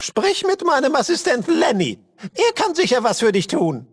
Fallout 2: Audiodialoge